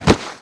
摔倒-YS070521.wav
通用动作/01人物/01移动状态/摔倒-YS070521.wav
• 声道 單聲道 (1ch)